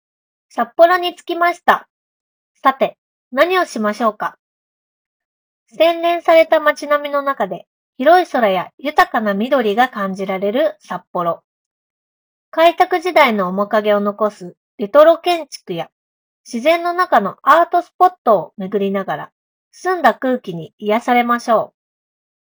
本製品にはブームマイクが付属しており、5マイク構成のAI ENC(環境ノイズキャンセリング)により快適なハンズフリー通話を行うことができます。
ブームマイクをイヤホンに装着した状態で、声を収録してみました。
▼OpenRock Link 20で収録した音声
iPhone 16 Proで撮影した動画と、イヤホン内蔵マイクで収録した音声を聴き比べてみると、内蔵マイクでは周囲の環境ノイズ（加湿器の稼働音や屋外の走行音など）が効果的に低減されており、装着者の声のみを明瞭に拾い上げることができています。